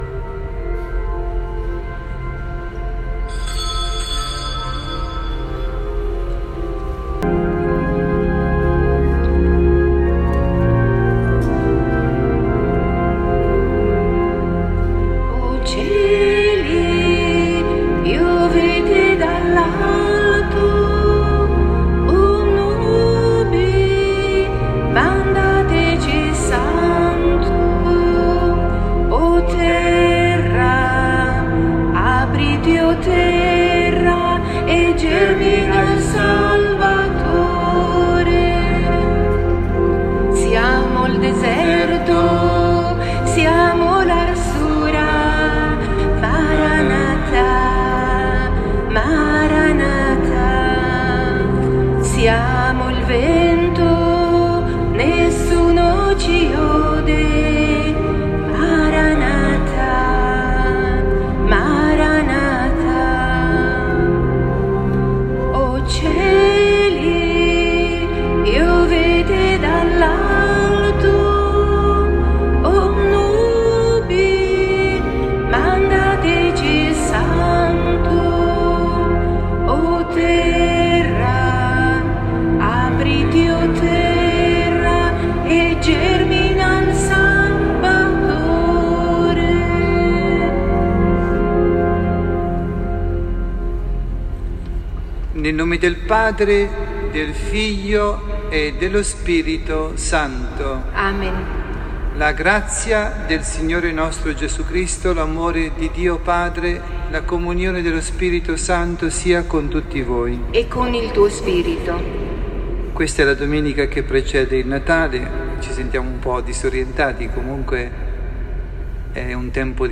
MESSA DOMENICALE PRENATALIZIA